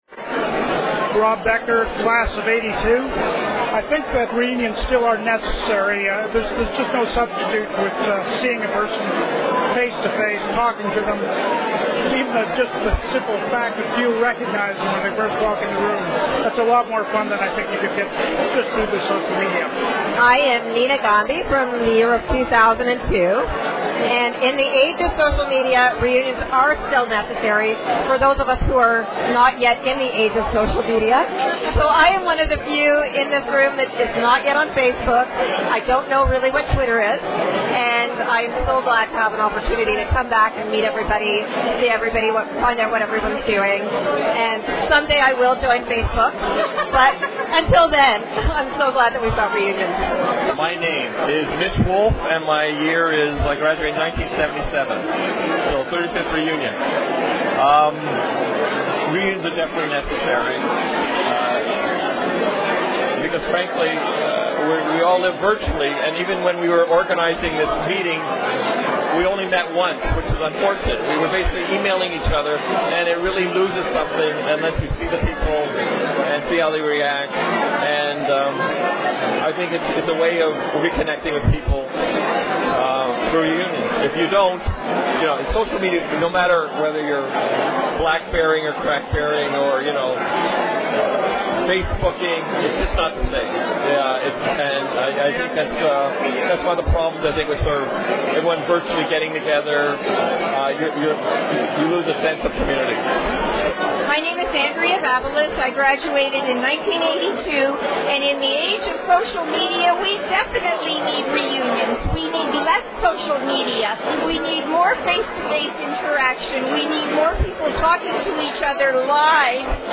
REUNION 2012 AUDIO FILE ON SOCIAL MEDIA COMMENTS.mp3